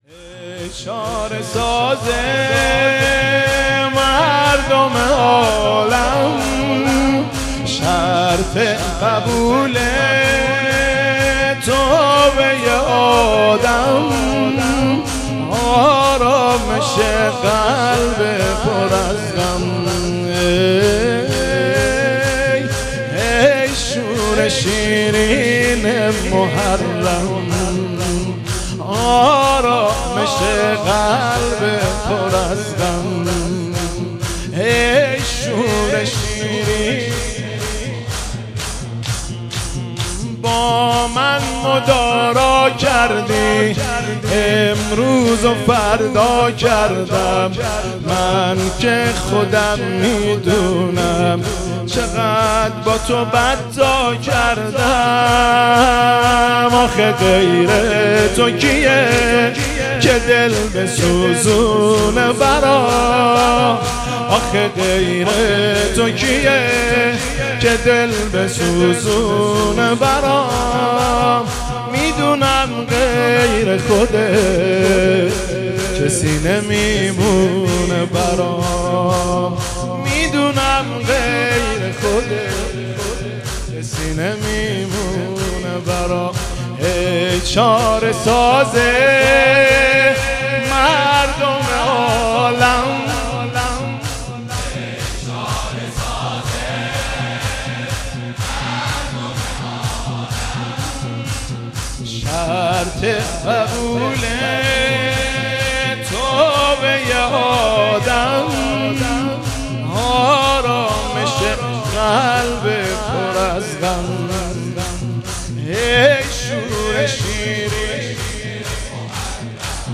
واحد ترکی